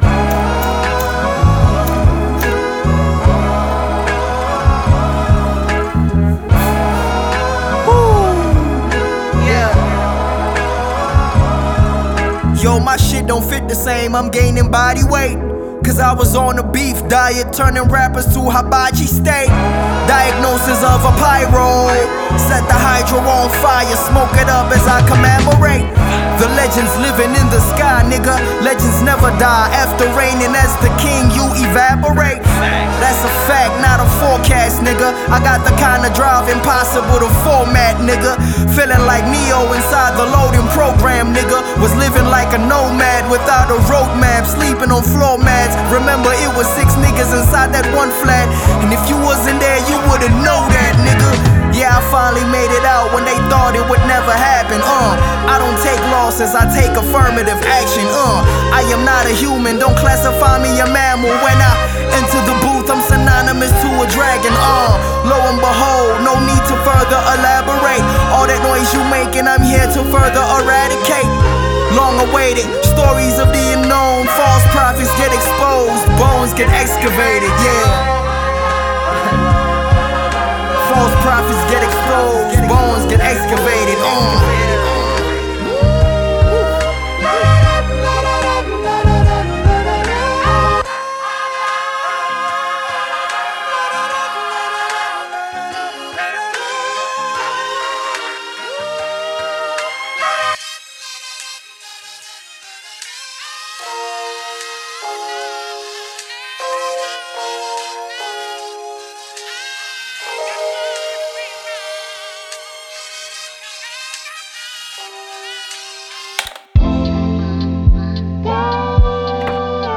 Genre: R&B/Soul/HipHop